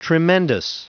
Prononciation du mot tremendous en anglais (fichier audio)
Prononciation du mot : tremendous